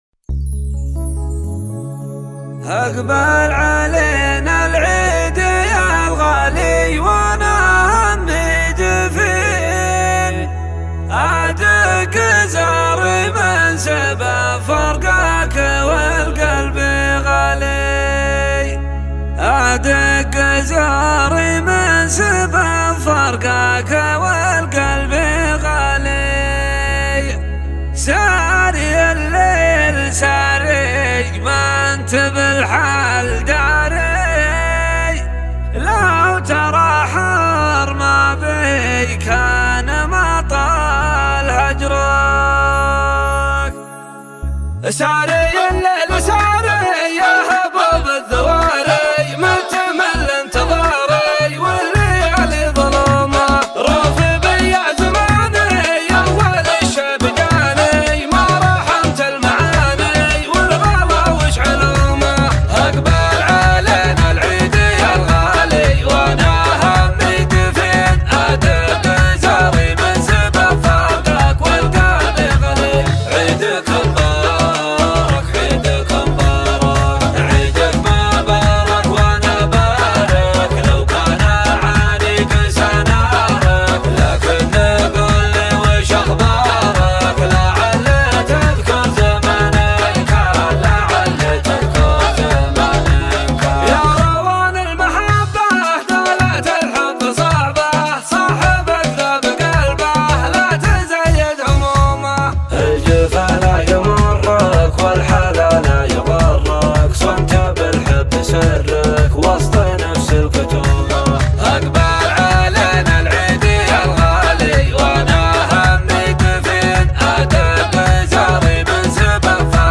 شيلات عيدية